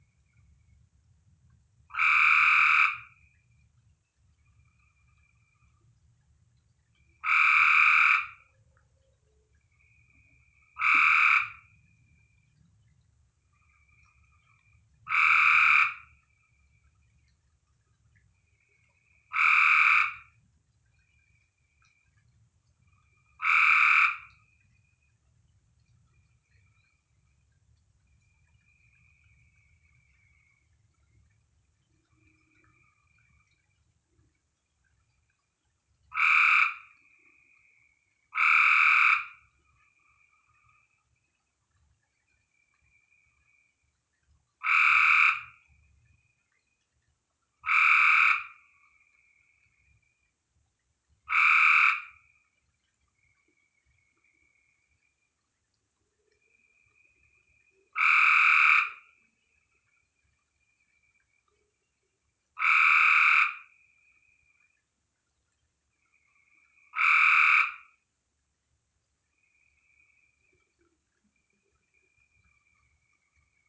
• The FROGS.  OMG, the FROGS.
WARNING:  Turn down the volume before you play this.
What I had not realized, until this year, is that the soothing “cheep, cheep, cheep” noise of massed frogs, from a distance, is composed of much ear-rattling croaking, when heard close up.
One of our (now) resident Gray Tree Frogs seems to have found my water barrels to his liking, and was seeking company in that location a couple of nights ago.  The recording above, off my cell phone, does not do justice to the smoke-alarm-like ear-piercing annoyance of that frog call, close up.
gray-tree-frog.wav